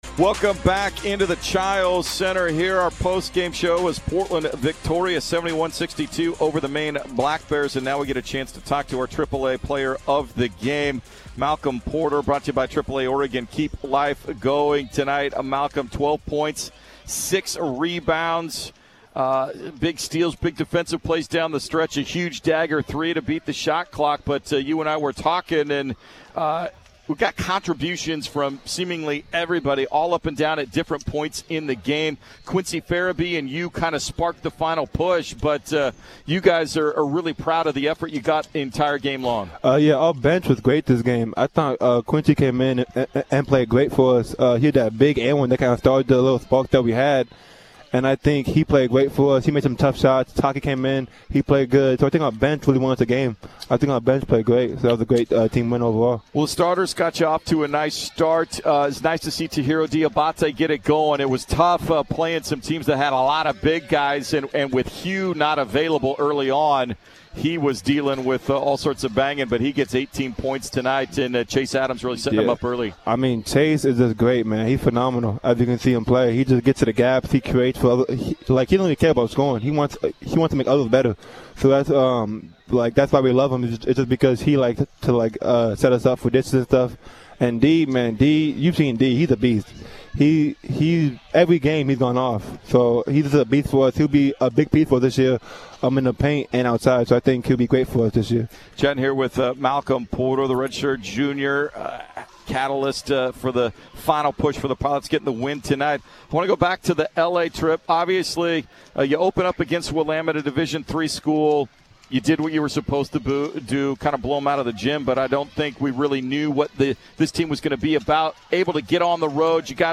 Men's Hoops Post-Game Interviews vs. Maine
Maine_interviews.mp3